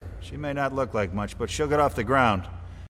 New Hope Deck Master describes the Crow — (audio)